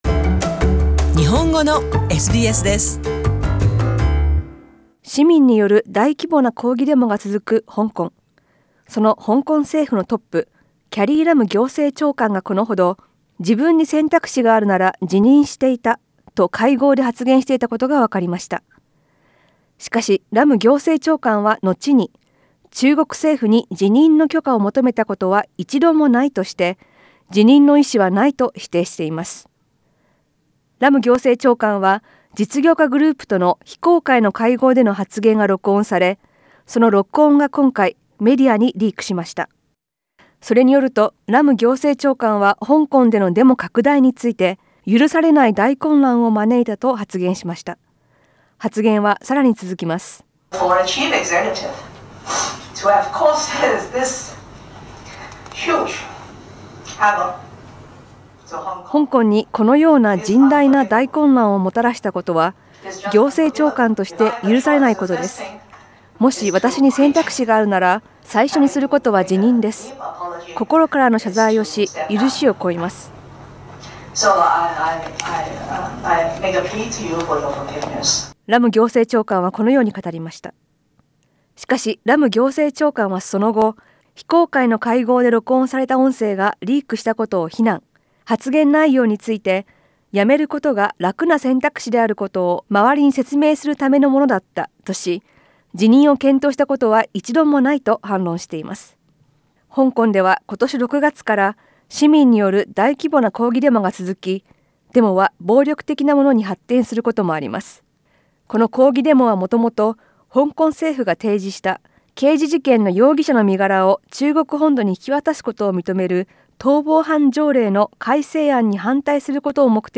詳しくは写真をクリックして、音声リポートを聞いてください。